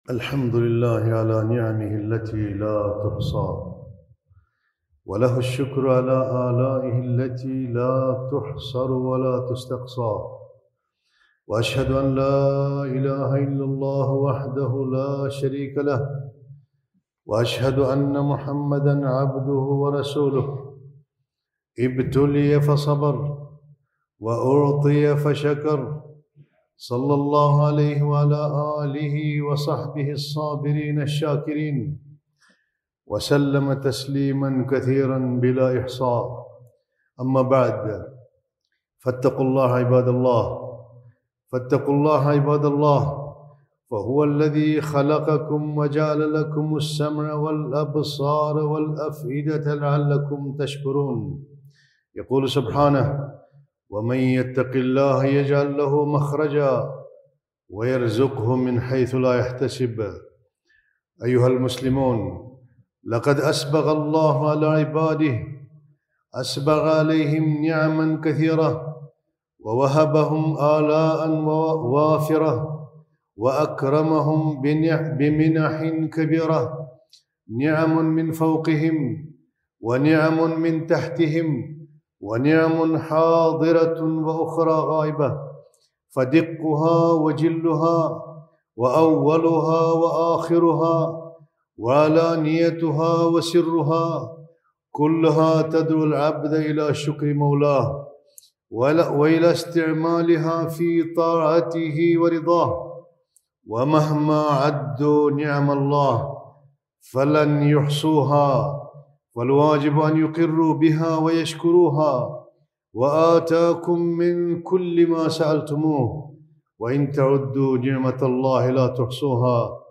خطبة - مكانة الشكر